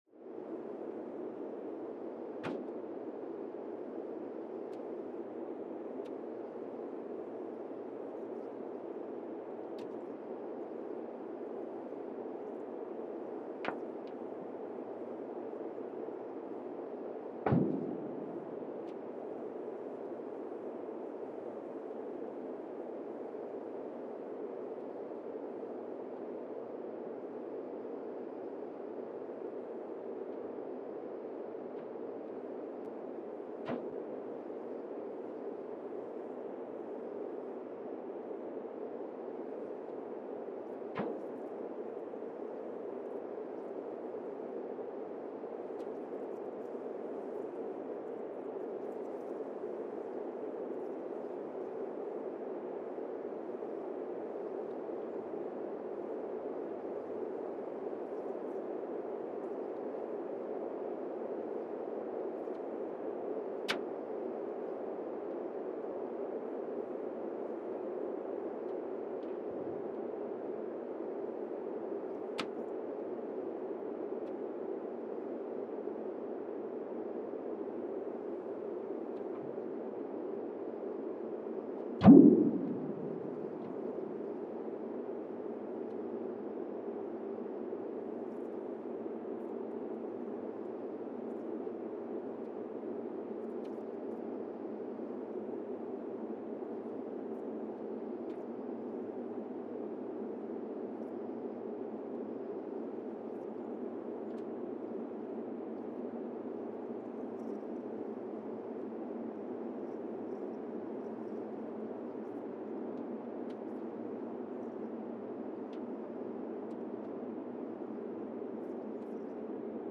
Monasavu, Fiji (seismic) archived on September 22, 2020
Station : MSVF (network: IRIS/IDA) at Monasavu, Fiji
Sensor : Teledyne Geotech KS-54000 borehole 3 component system
Speedup : ×1,800 (transposed up about 11 octaves)
Loop duration (audio) : 05:36 (stereo)
SoX post-processing : highpass -2 90 highpass -2 90